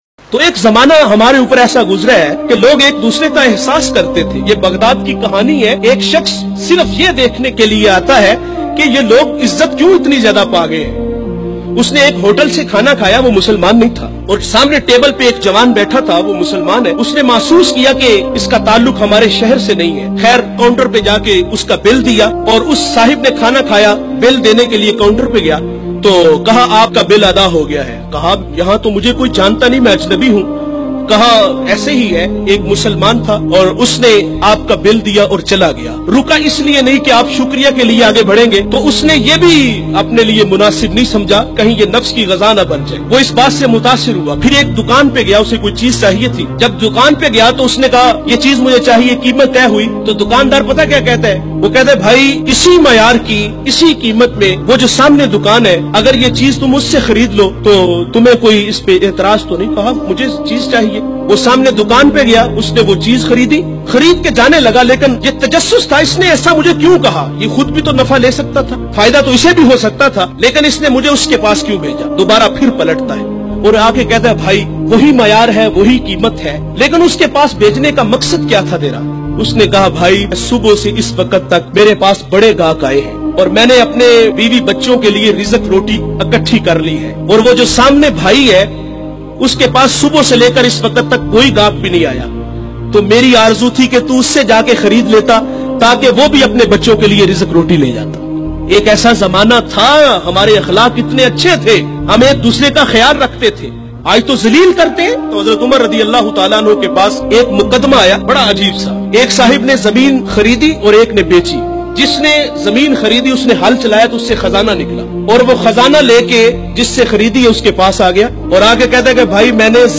ik muslamn ny dosry muslman ki zameen bechi islamicdb bayan Hm.mp3